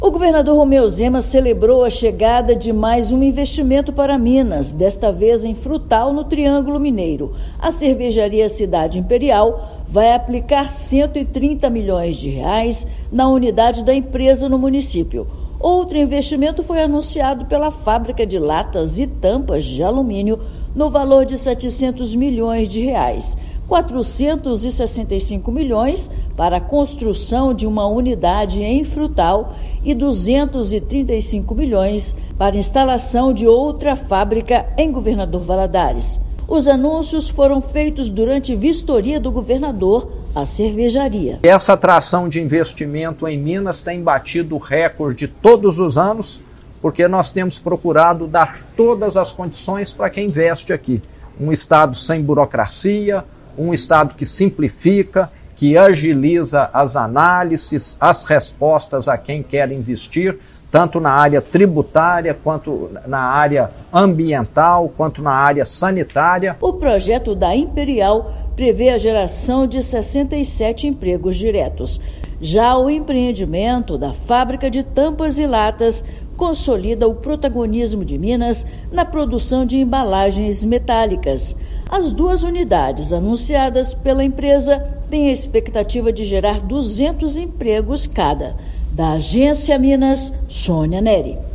Em anúncio no Triângulo Mineiro que somou R$ 830 milhões no estado, governador destacou marca expressiva alcançada desde 2019. Ouça matéria de rádio.